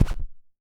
CYCdh_Kurz08-Scratch02.wav